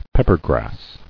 [pep·per·grass]